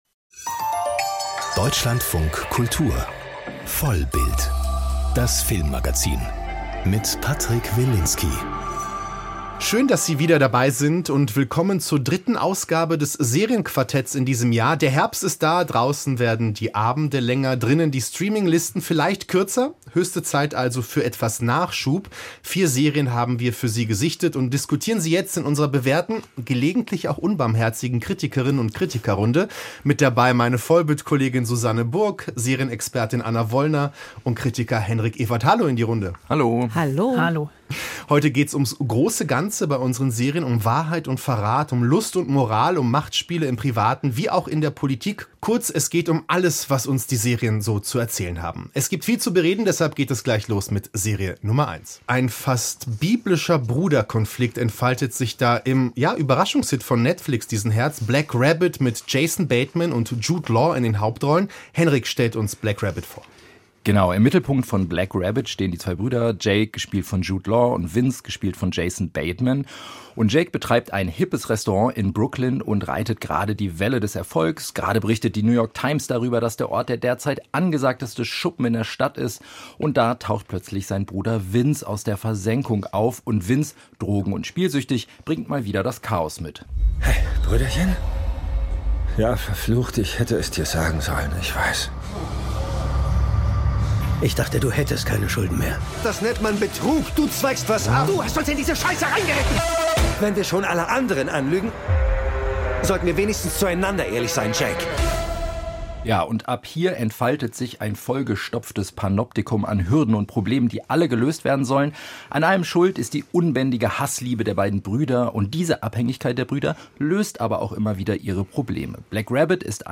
Email Audio herunterladen Unser Serienquartett diskutiert über die wichtigsten Serien-Neustarts. Im Fokus: die ARD-Serie „naked“, die ungewohnt offen und ungeschönt von Sexsucht erzählt.